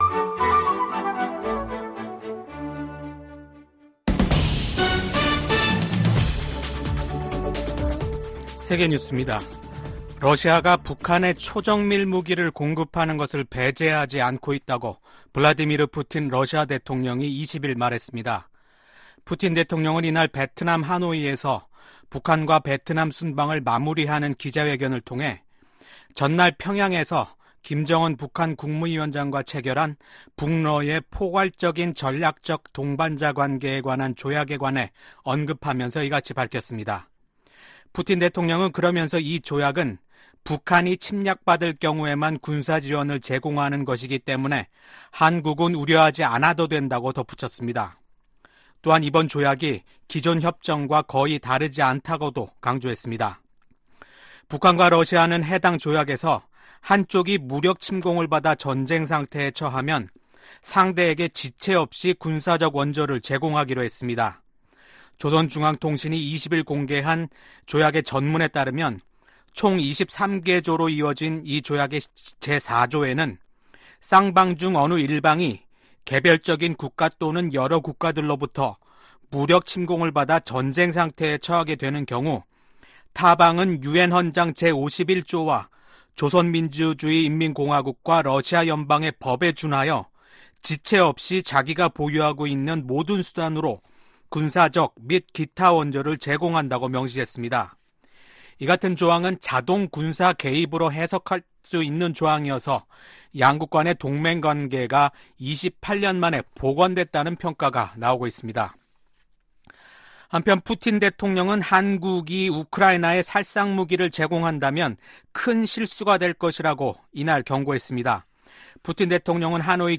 VOA 한국어 아침 뉴스 프로그램 '워싱턴 뉴스 광장' 2024년 6월 21일 방송입니다. 북한과 러시아가 새 조약을 통해 어느 한쪽이 무력침공을 받아 전쟁 상태에 놓이면 지체 없이 군사적 원조를 제공하기로 했습니다. 미국 정부는 이에 대해 한반도 평화와 안정, 국제 비확산 체제, 러시아의 잔인한 우크라이나 침략 전쟁 등을 지적하며 심각한 우려 입장을 나타냈습니다. 한국 정부도 국제사회의 책임과 규범을 저버리는 행위라며 이를 규탄한다고 밝혔습니다.